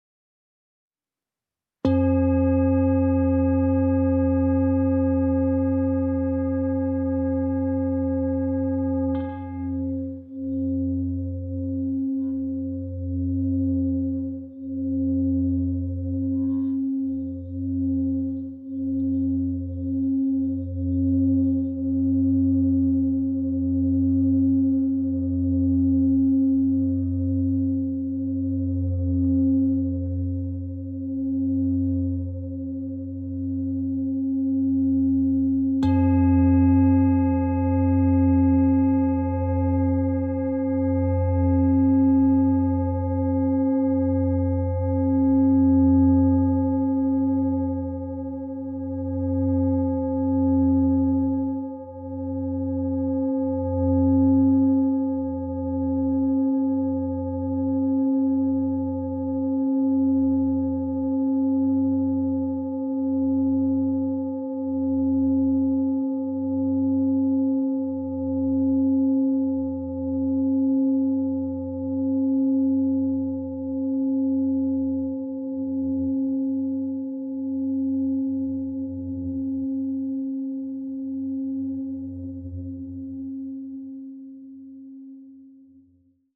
Meinl Sonic Energy Universal Series Singing Bowl - 2300 g (SB-U-2300)